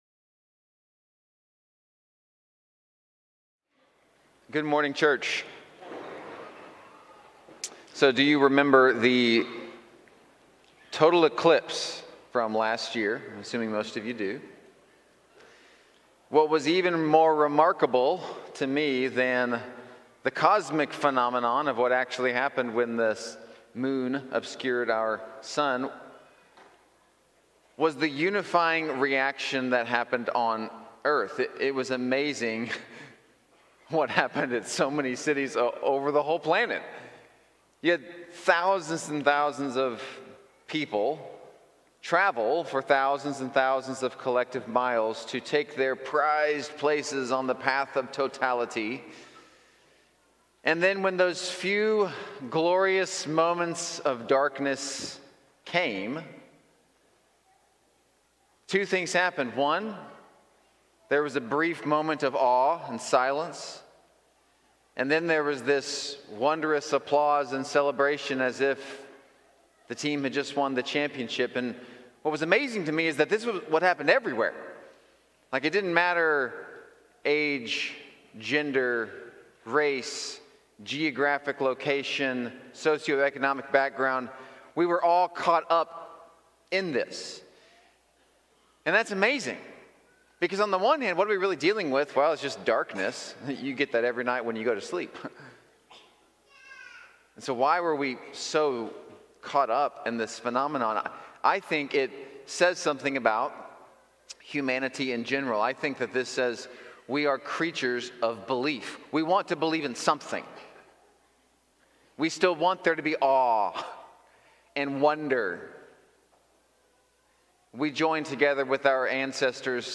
Series: Believe in Me, Sunday Morning